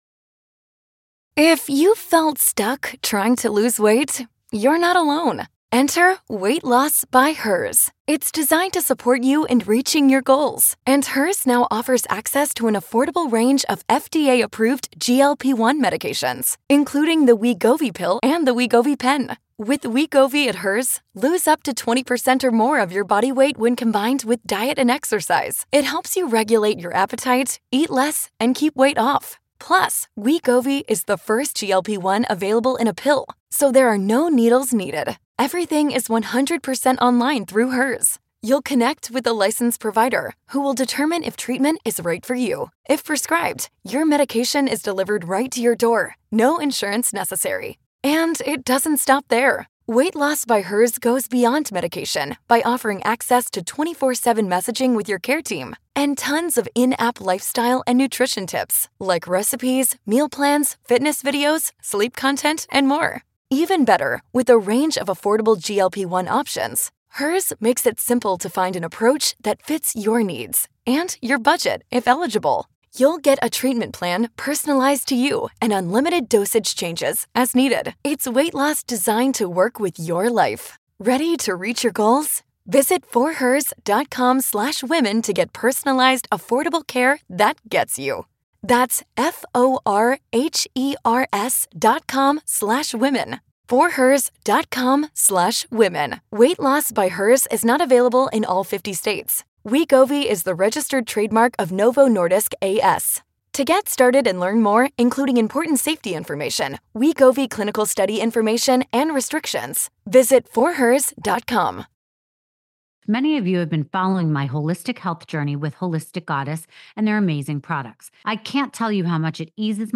He sits down in person with Rachel to tell her how it all began and how he got to where he is now.